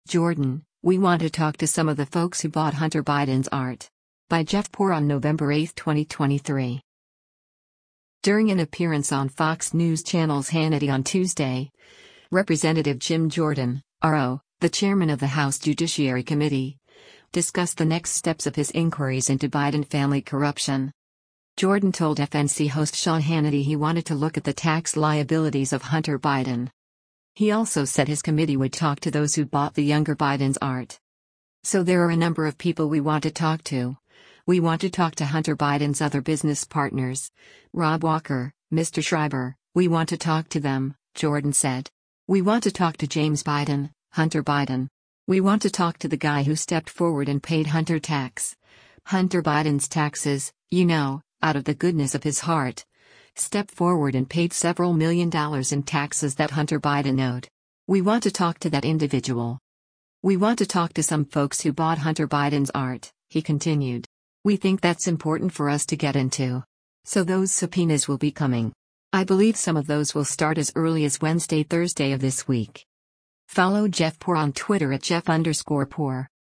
During an appearance on Fox News Channel’s “Hannity” on Tuesday, Rep. Jim Jordan (R-OH), the chairman of the House Judiciary Committee, discussed the next steps of his inquiries into Biden family corruption.
Jordan told FNC host Sean Hannity he wanted to look at the tax liabilities of Hunter Biden.